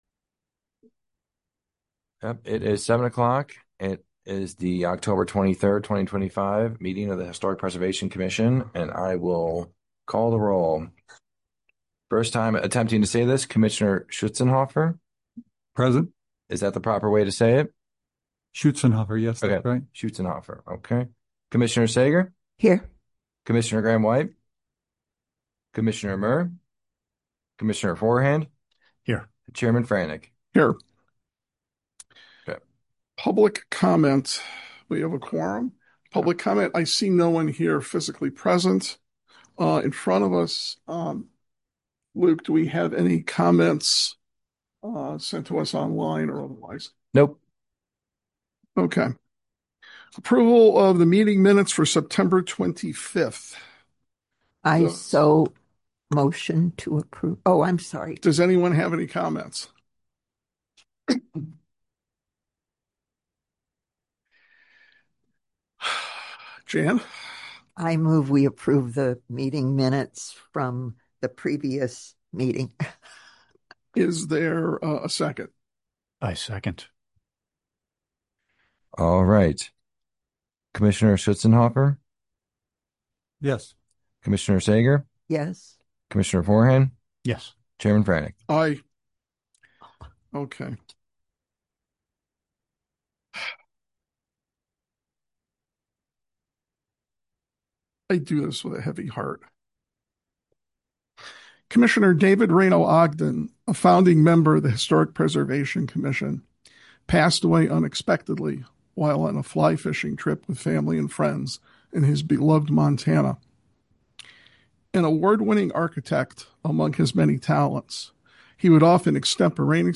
Historic Preservation Commission Meeting
Village Hall - 400 Park Avenue - River Forest - IL - 1st Floor - Community Room